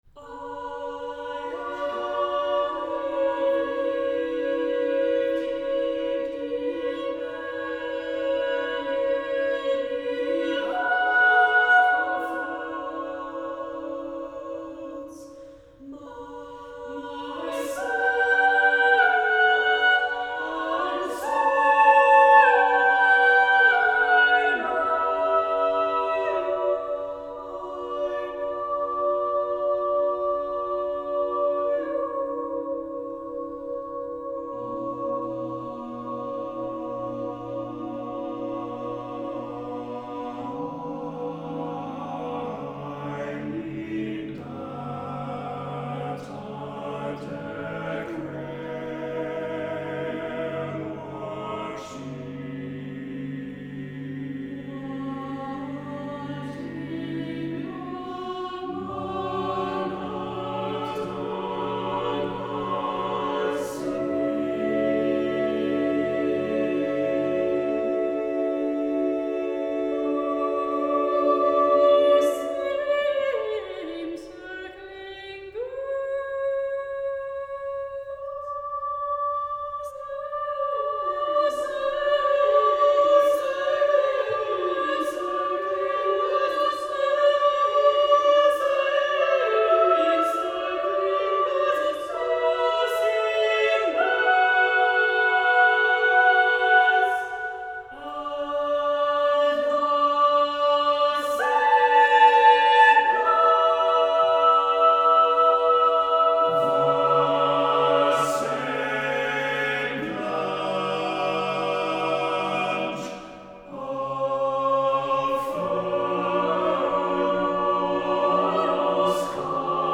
SATB Chorus with Divisi